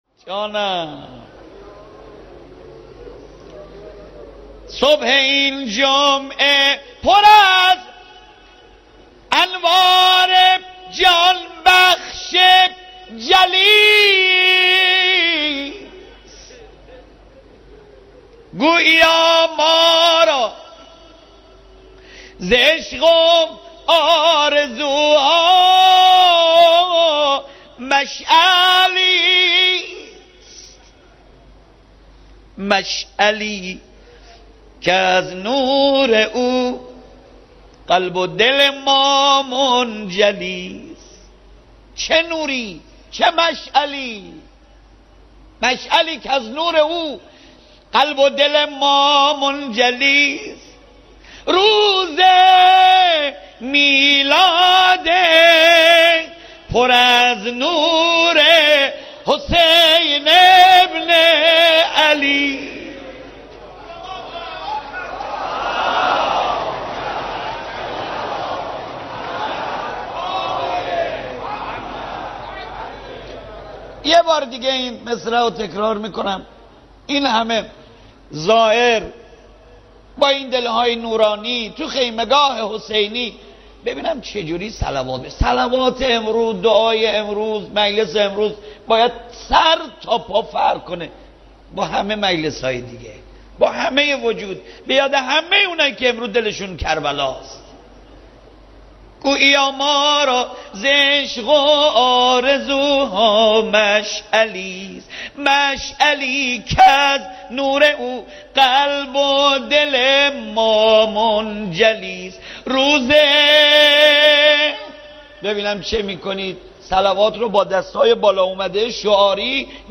میلاد امام حسین(ع)/شعرخوانی